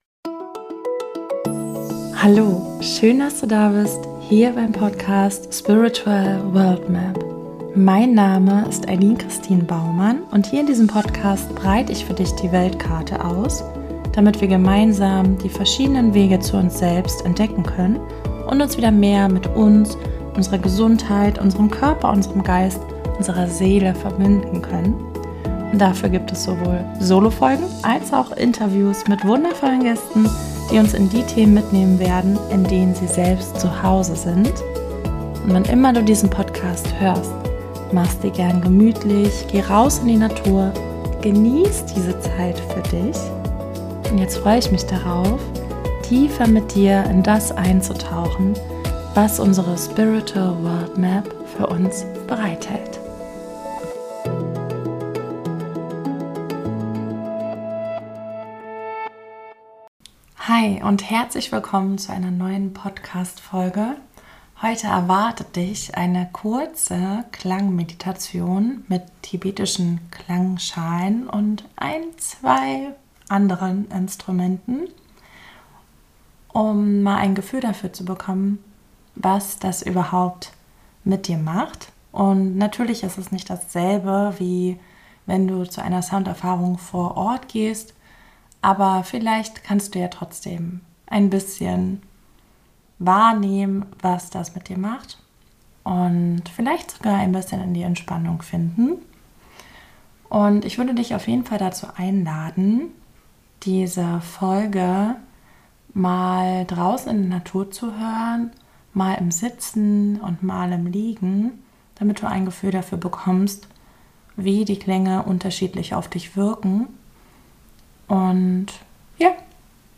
Folge 3 : Klangschalen Meditation ~ Spiritual World Map Podcast
Beschreibung vor 4 Monaten In der heutigen Podcast Folge erwartet dich eine kurze Sound Meditation mit tibetischen Klangschalen.
Aber so kannst du einen ersten Eindruck gewinnen, wie tibetische Klangschalen klingen und auf dich wirken können.